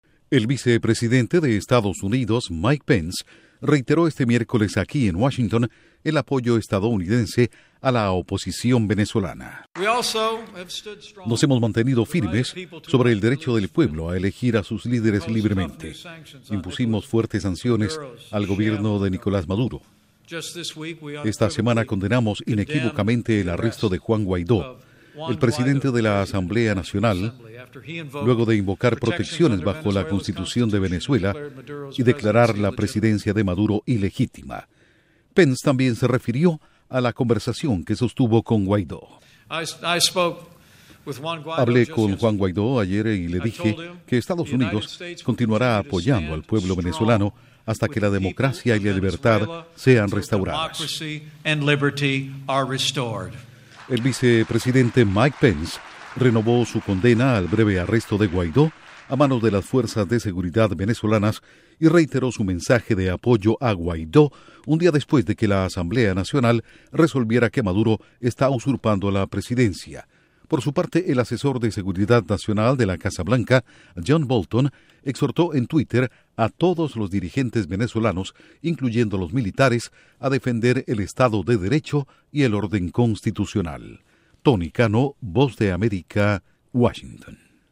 Duración: 1:25 2 audios de Mike Pence/Vicepresidente de EE.UU.